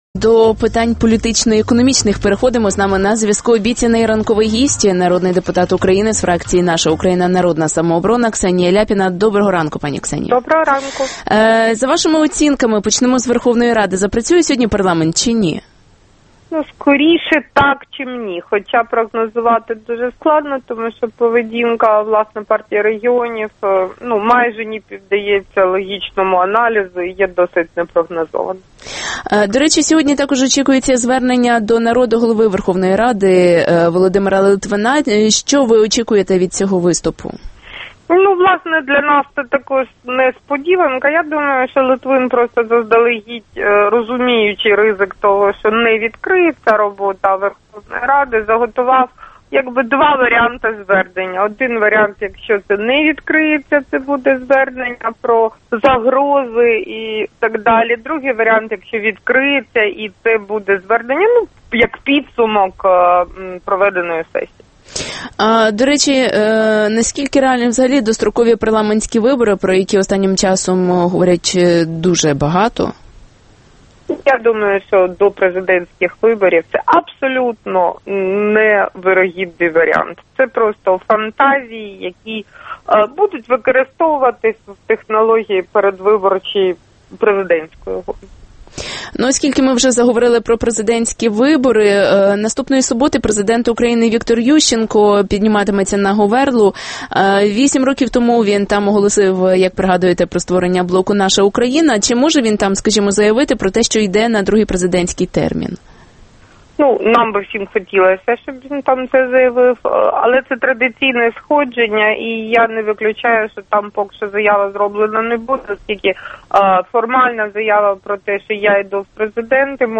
Народний депутат Ксенія Ляпіна в ефірі Радіо Свобода про поточну політичну ситуацію.